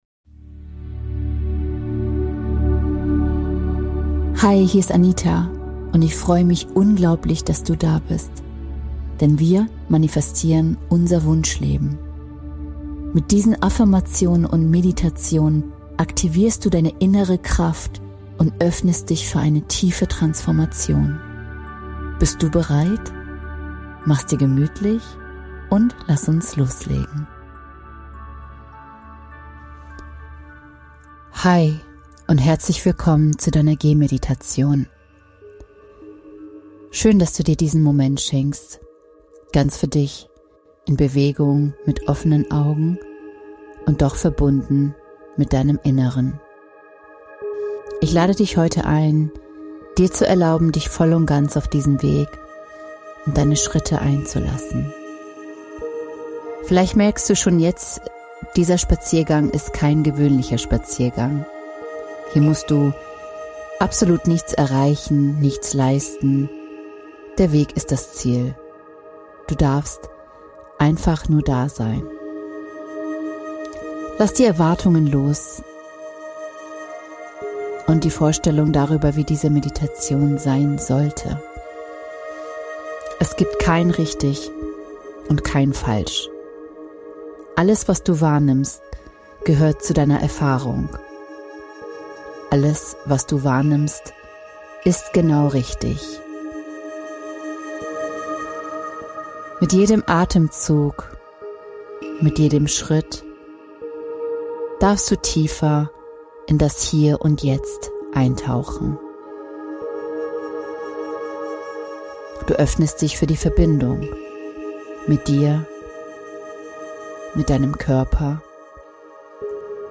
Entdecke in dieser Folge, wie du durch achtsames Gehen in den gegenwärtigen Moment kommst, dich mit deinem Körper und deiner Umgebung verbindest und innere Ruhe findest. Lass dich von einer Geh-Meditation begleiten, die deinen Atem, deine Schritte und die Schönheit der Natur bewusst erfahrbar macht....